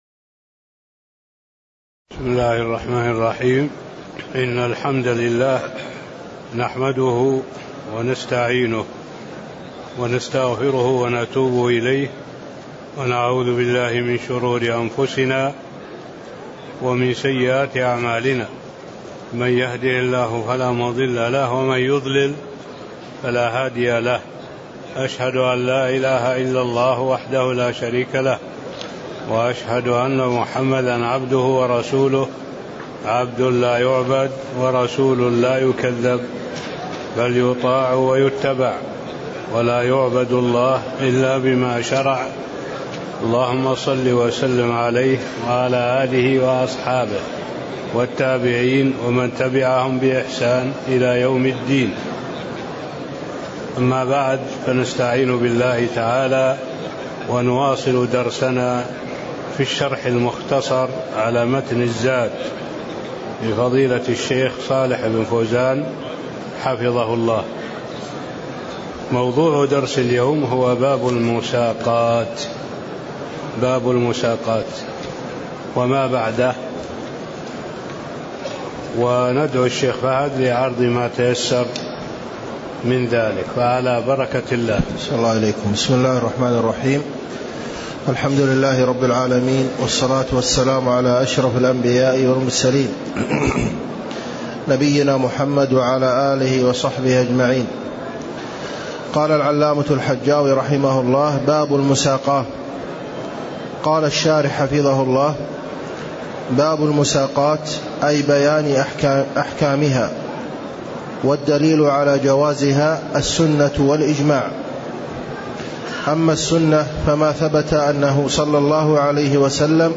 تاريخ النشر ١٧ ربيع الثاني ١٤٣٥ هـ المكان: المسجد النبوي الشيخ: معالي الشيخ الدكتور صالح بن عبد الله العبود معالي الشيخ الدكتور صالح بن عبد الله العبود باب المساقاة (13)